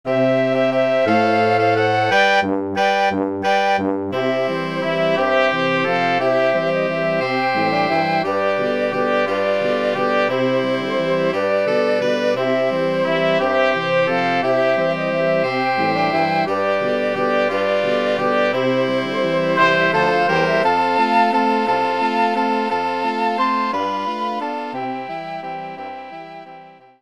Orkiestrowa
ludowe